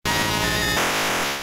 Cri de Raichu K.O. dans Pokémon Diamant et Perle.